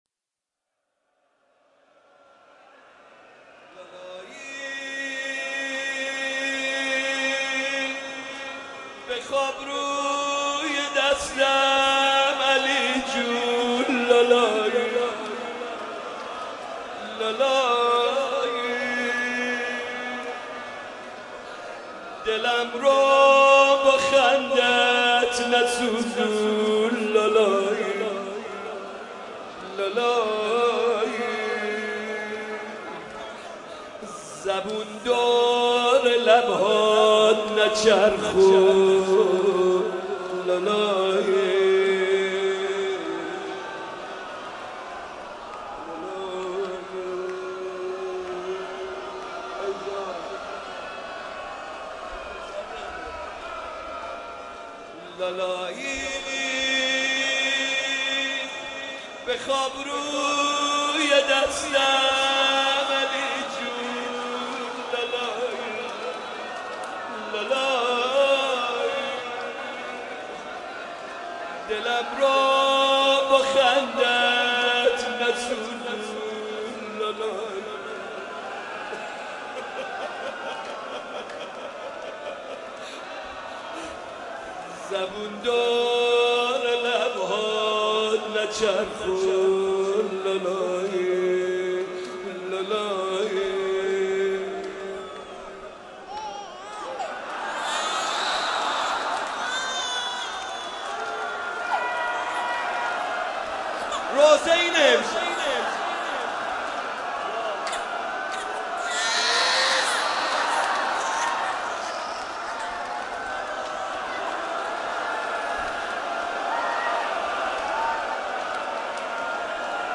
لالایی بخواب روی دستم علی جون لالایی _ روضه حاج محمدرضا طاهری شب هفتم محرم 96/07/5